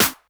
Snare_33.wav